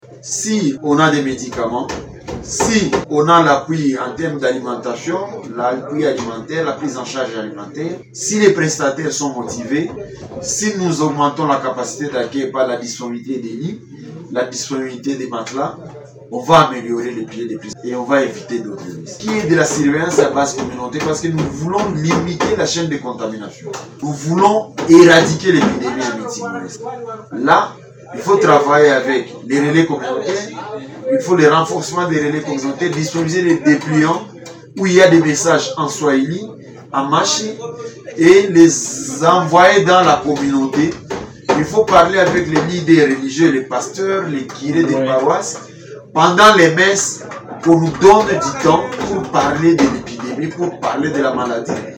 Dans un entretien avec Radio Maendeleo